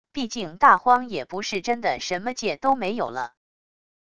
毕竟大荒也不是真的什么界都没有了wav音频生成系统WAV Audio Player